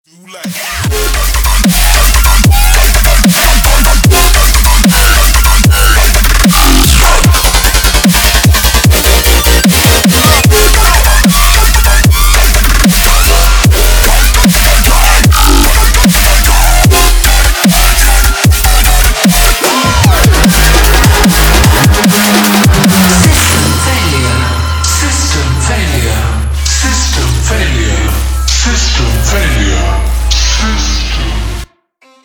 DubStep обрезка на телефон